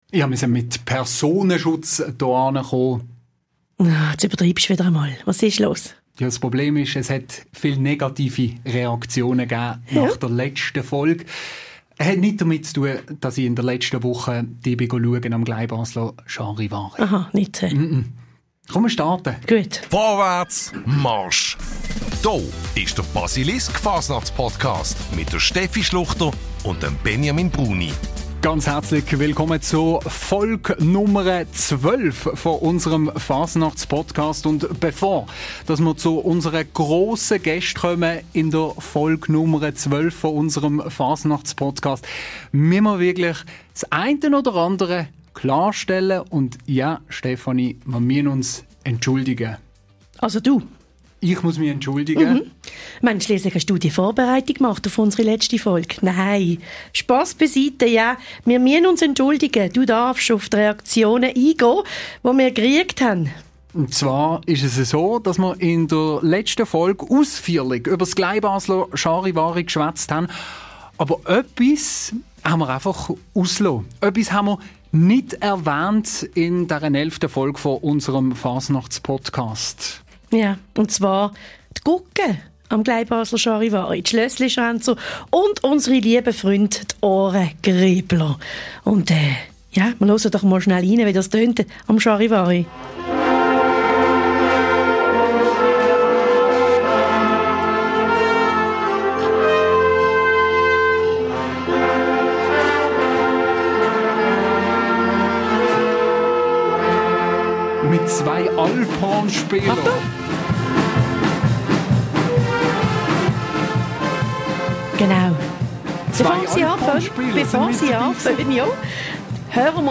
Dabei geht es um ihre tiefe Faszination für die Fasnacht – und ganz nebenbei wird auch das Geheimnis rund um den Rekord im Charivari-Keller gelüftet. Im Theater Scala läuft aktuell die Vorfasnachts-Veranstaltung «Läggerli».